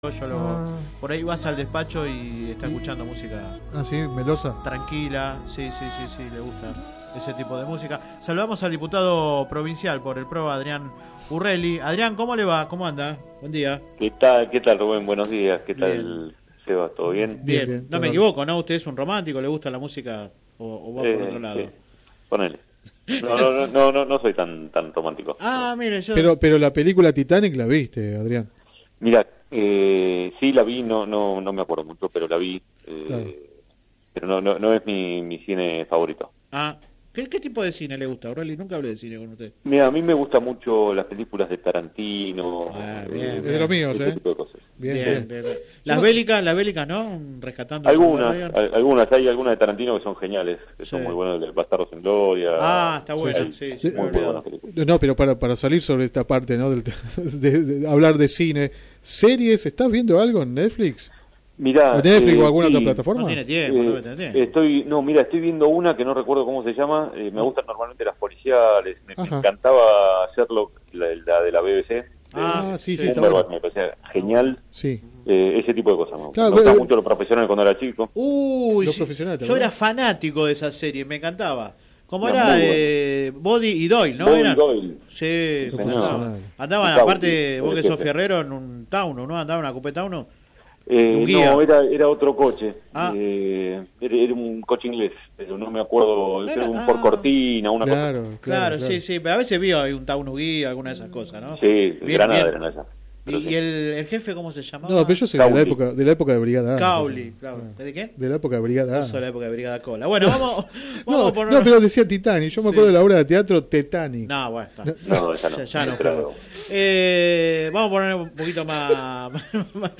El diputado provincial por el PRO, Adrián Urreli brindó una extensa entrevista en el programa radial Sin Retorno (lunes a viernes de 10 a 13 por GPS El Camino FM 90 .7 y AM 1260). Habló de la decisión de Mauricio Macri de no presentar una candidatura presidencial, su nuevo rol y la interna del partido. También opinó sobre el esquema electoral provincial y salió al cruce de críticas de una parte de la oposición en Lanús.